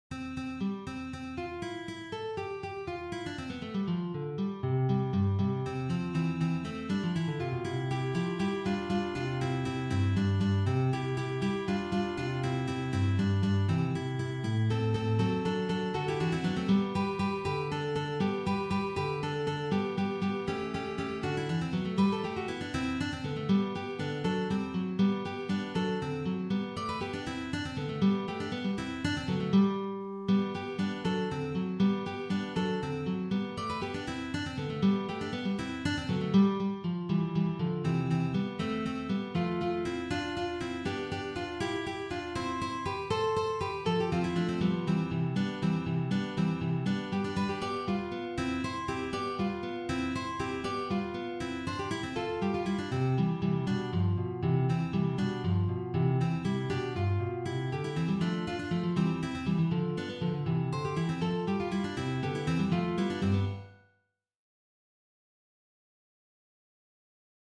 for three guitars
Baroque selection